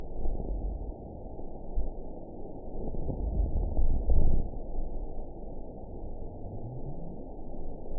event 920010 date 02/06/24 time 20:19:37 GMT (1 year, 4 months ago) score 8.43 location TSS-AB09 detected by nrw target species NRW annotations +NRW Spectrogram: Frequency (kHz) vs. Time (s) audio not available .wav